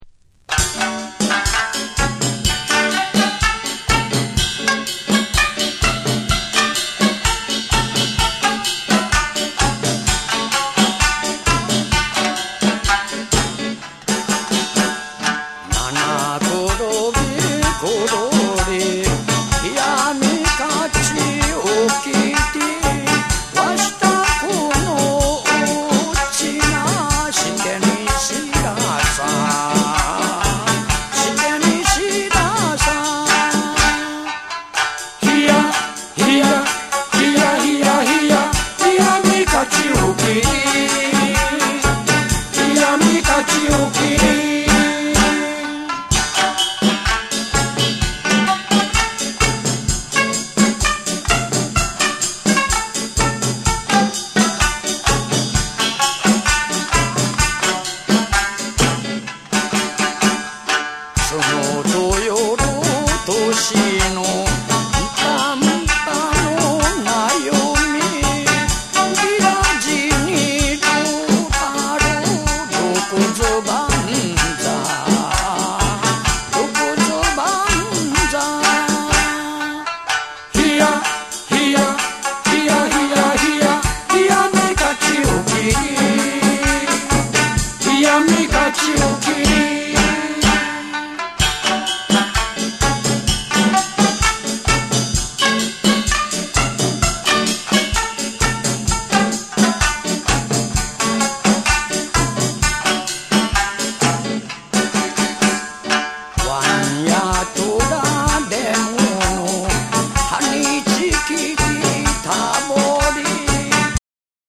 シンバルワークを効かせたキューバン・ジャズにもシンクロするリズムに、三線とソウルフルとも響く歌声が巡る
JAPANESE / WORLD / NEW RELEASE(新譜)